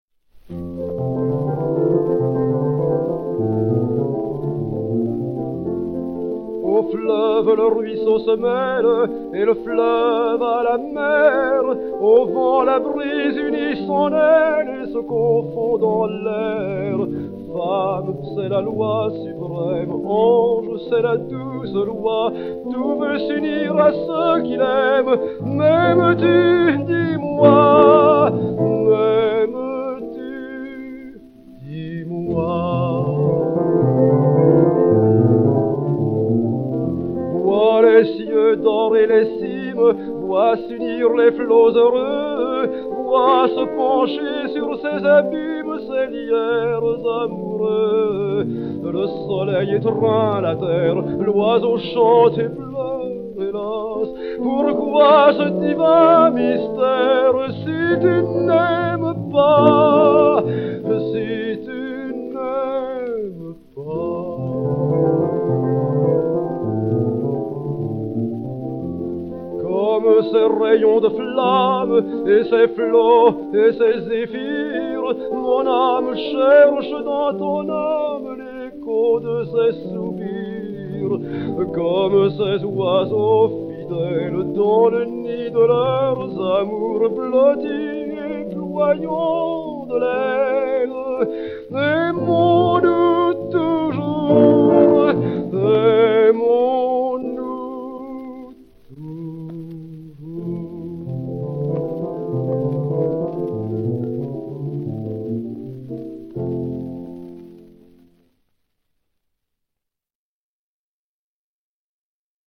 Mélodie, poésie de Jules BARBIER, musique de Charles GOUNOD (1872).
Reynaldo Hahn s'accompagnant au piano Pleyel
L 393, enr. en 1927